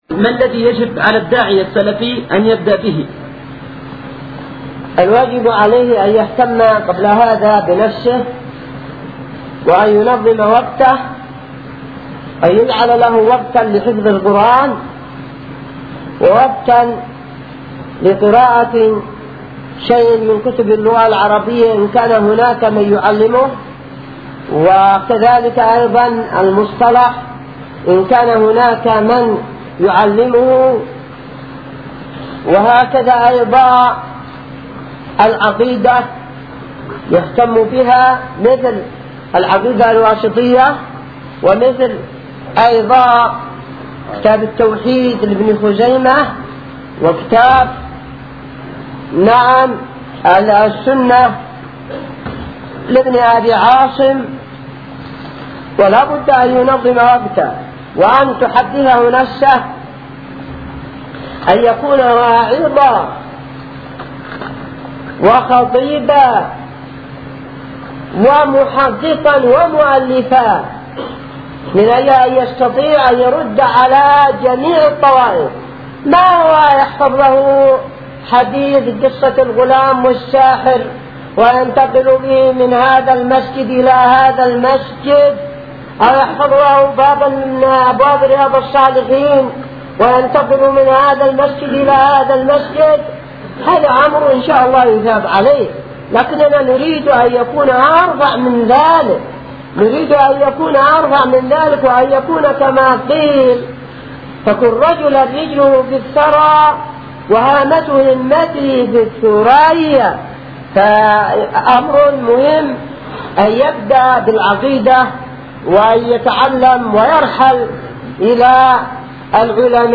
| فتاوى الشيخ مقبل بن هادي الوادعي رحمه الله
muqbel-fatwa2801.mp3